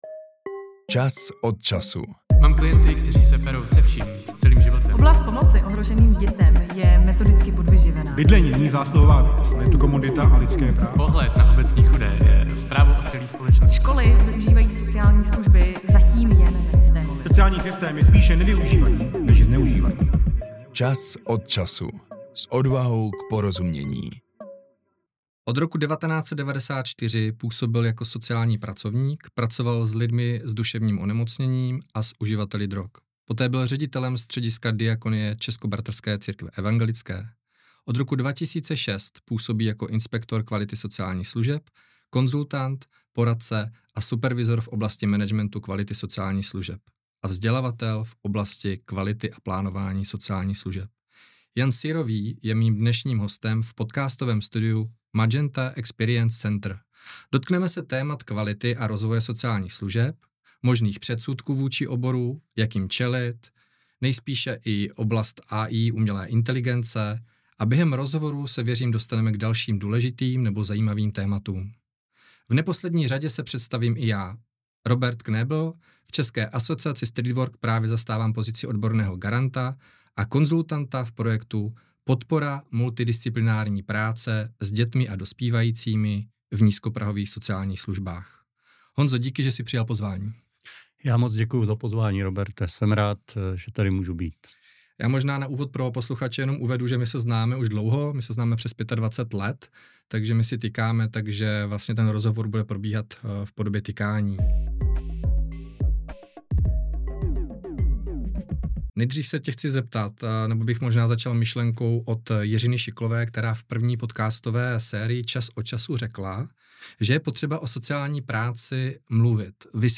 Jak se mění obor sociální práce v informační společnosti a pomáhají technologie klientům ke zlepšení jejich sociální situace? Mohou se zapojit místní komunity? Jaké jsou výzvy a nedostatky nízkoprahových služeb? Rozhovor